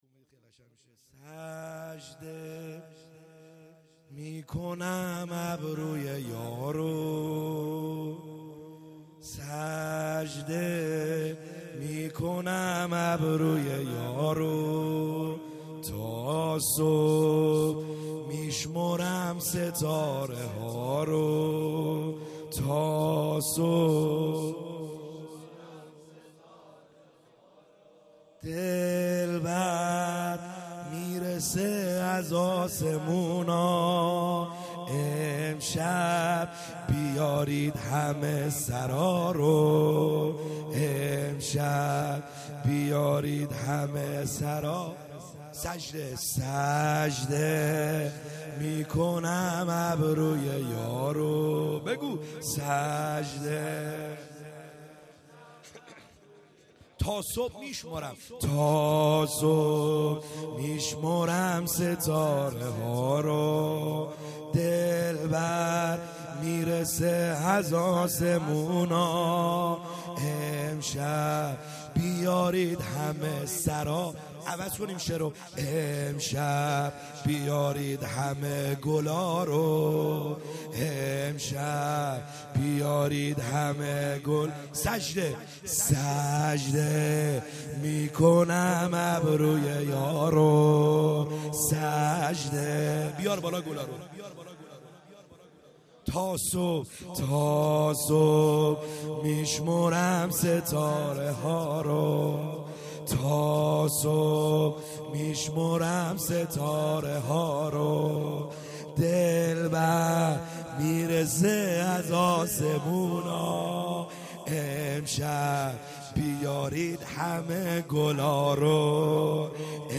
خیمه گاه - بیرق معظم محبین حضرت صاحب الزمان(عج) - سرود | سجده میکنم ابروی